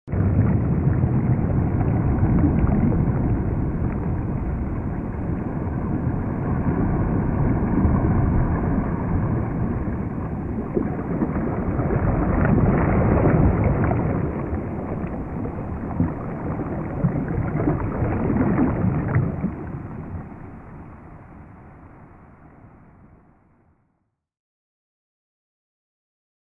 جلوه های صوتی
دانلود آهنگ وال 22 از افکت صوتی انسان و موجودات زنده
برچسب: دانلود آهنگ های افکت صوتی انسان و موجودات زنده دانلود آلبوم صدای حیوانات آبی از افکت صوتی انسان و موجودات زنده